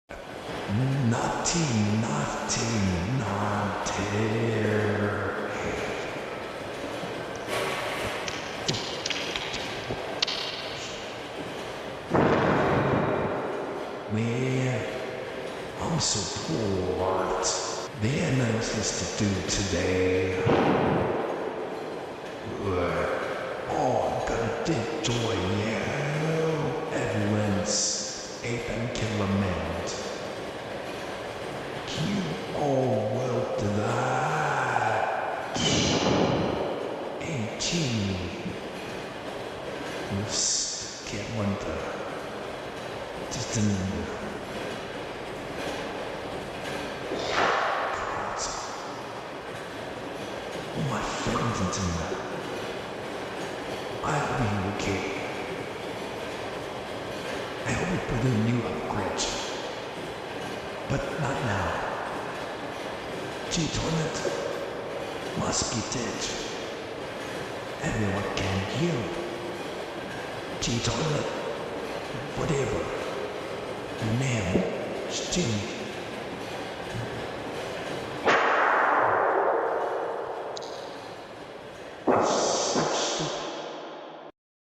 Mothership Astro Toilet Voice Lines